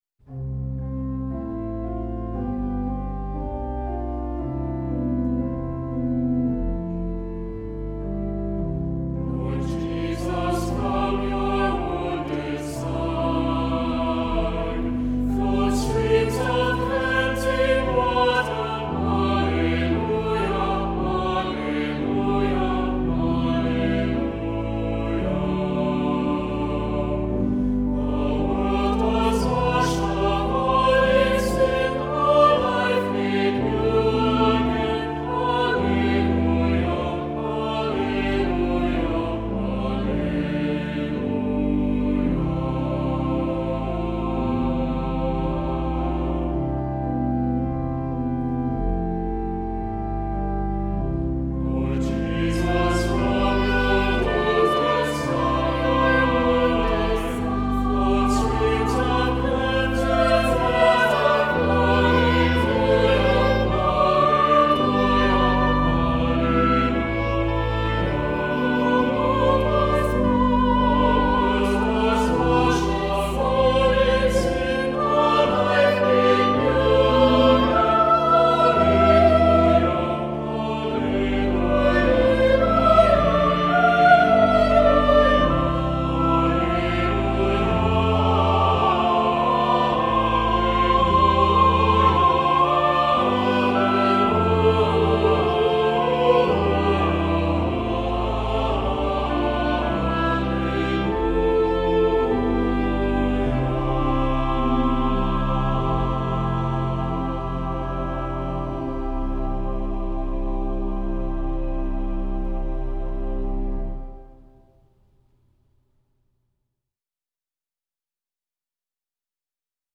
Voicing: Unison with descant; SATB; Assembly